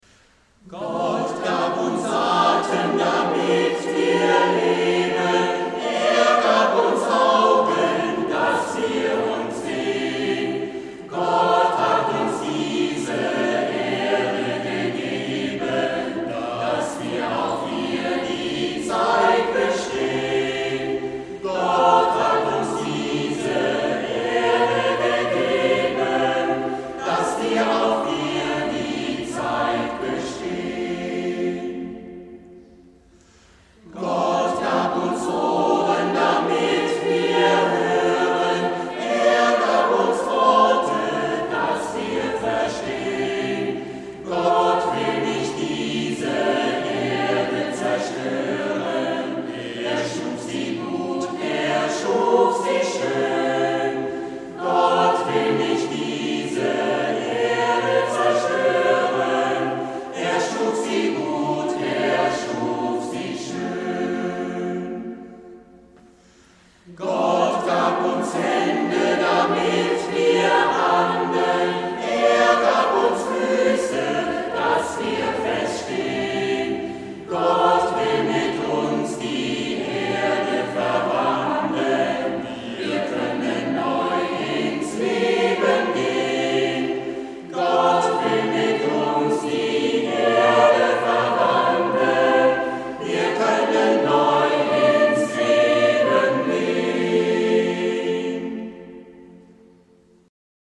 Auferstehungsmesse Ostern 2011